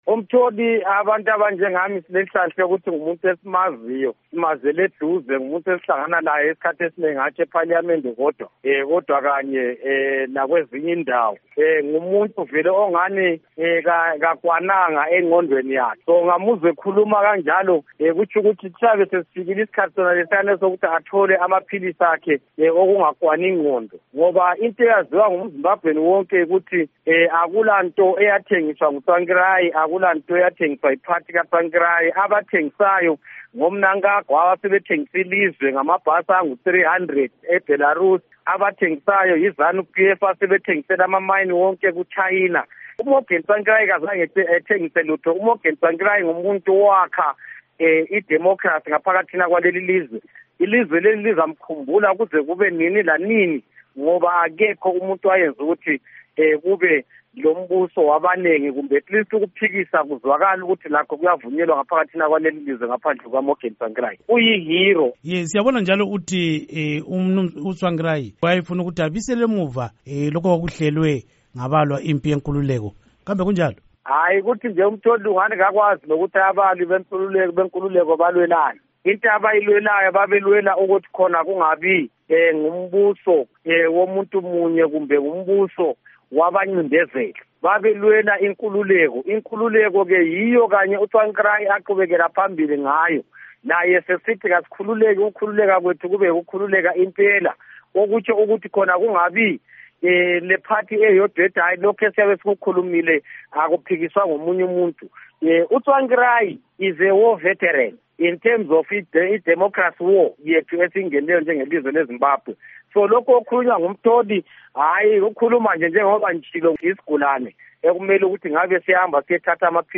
Ingxoxo Esiyenze LoMnu. Prince Dubeko Sibanda